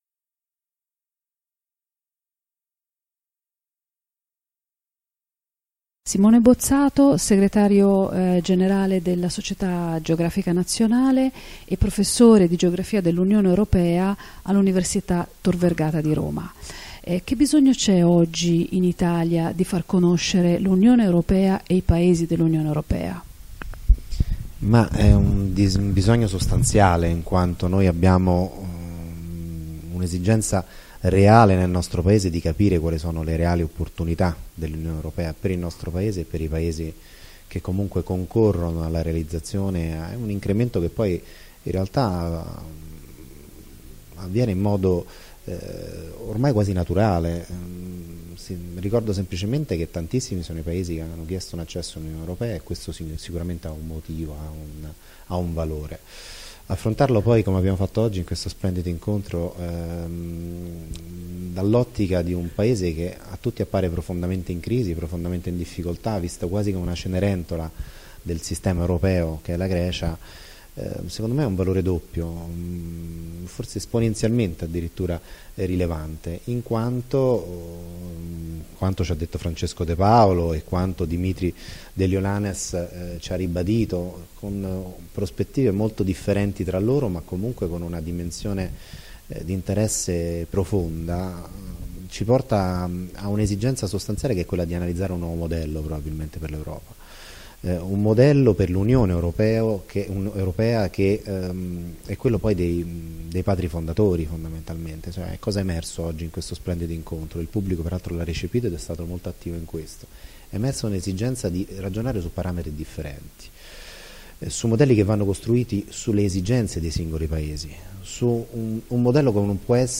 Roma, 4 marzo 2015 libreria L'Agonauta, in occasione dell'iniziativa "Conosci l’Europa: 28 Paesi in 180 giorni”.